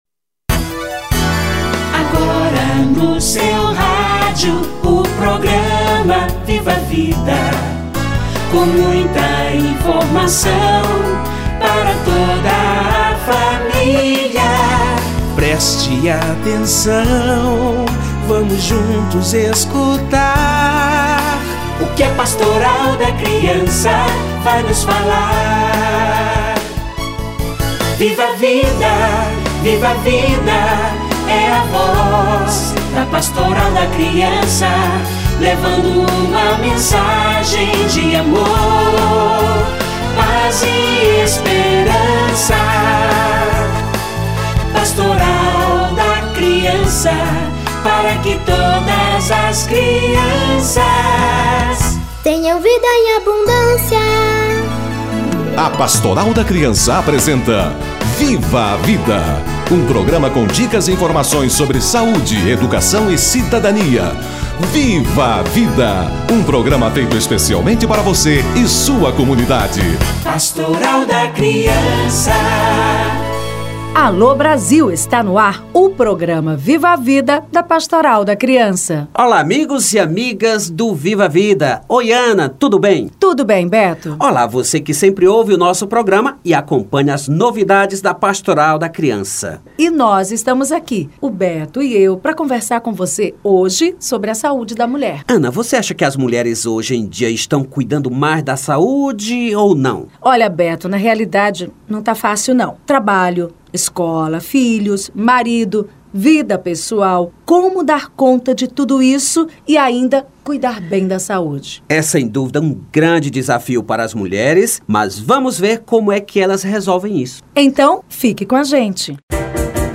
Saúde da mulher - Entrevista